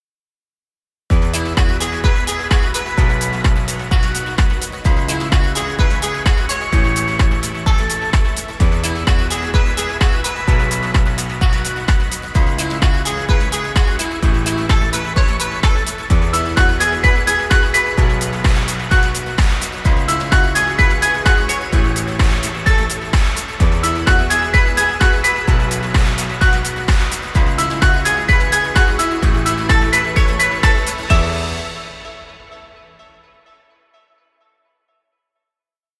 Children music. Background music Royalty Free.